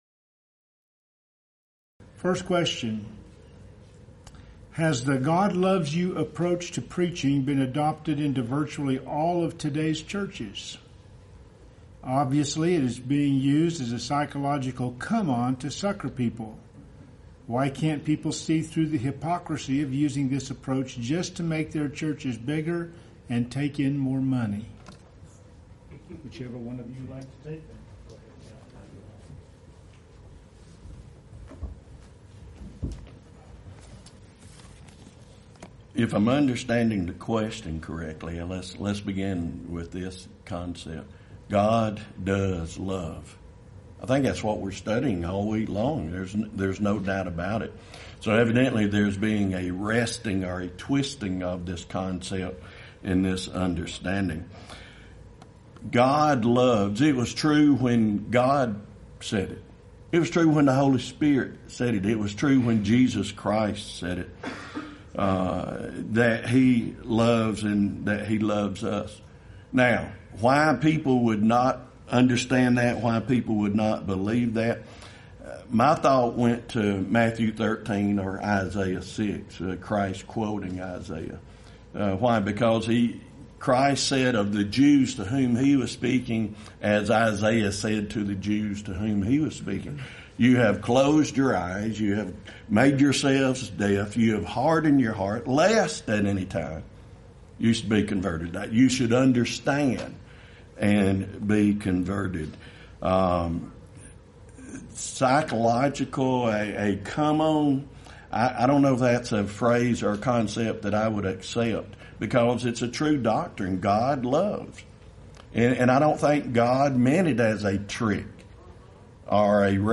Title: Tuesday Open Forum Speaker(s): Various
Event: 26th Annual Lubbock Lectures Theme/Title: God is Love